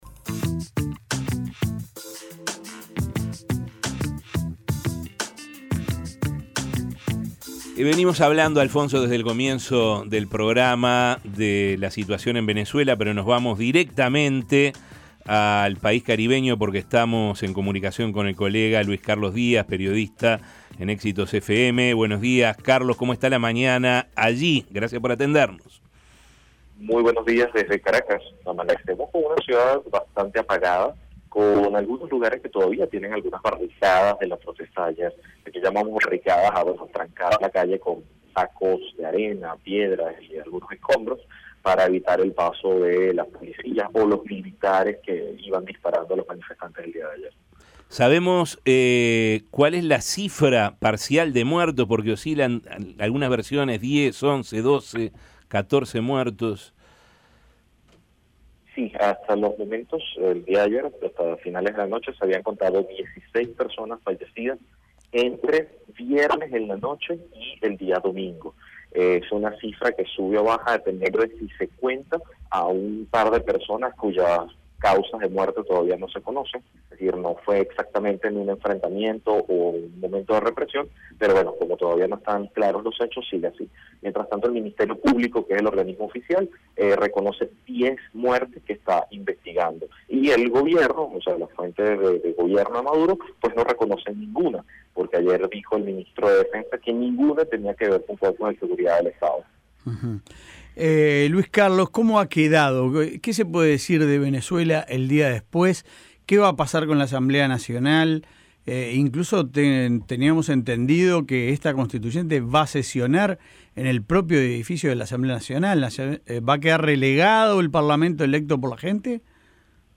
Esta fiscal es una "ficha del chavismo" pero la lealtad se rompió y el gobierno de Nicolás Maduro ahora busca cercarla relató el colega, en diálogo con La Mañana de El Espectador.